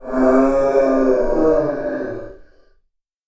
smashed glass, random sounds